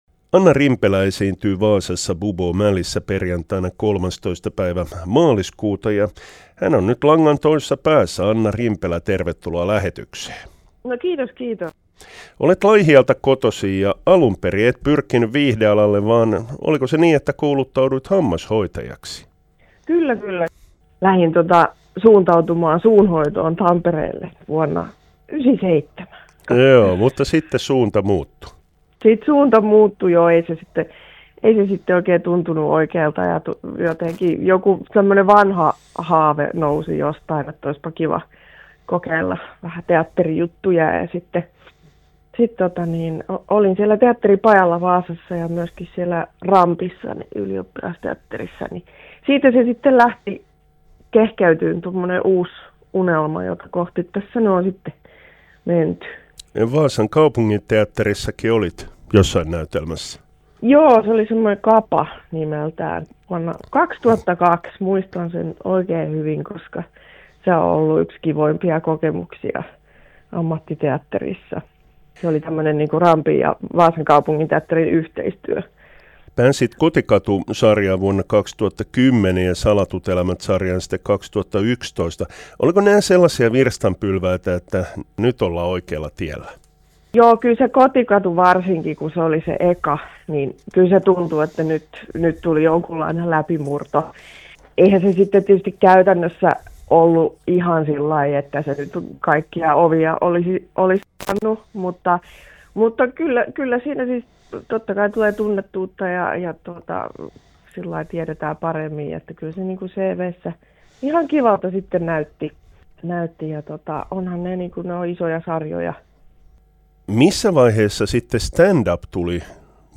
Anna Rimpelä on TV:stä tuttu näyttelijä ja koomikko.